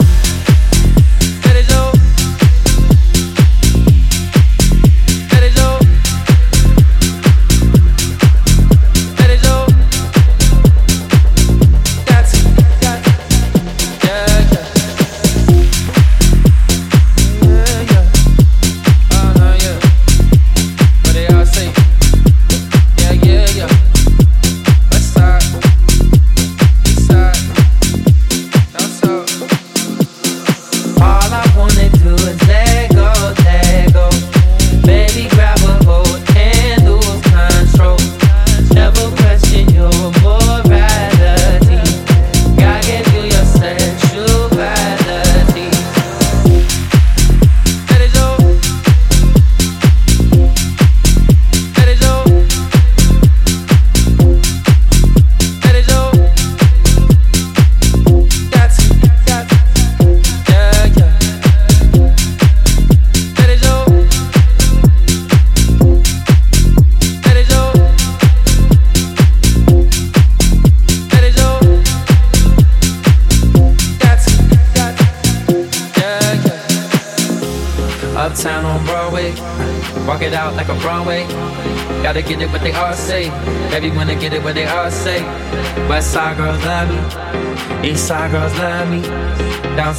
ジャンル(スタイル) DEEP HOUSE / SOULFUL HOUSE / TECH HOUSE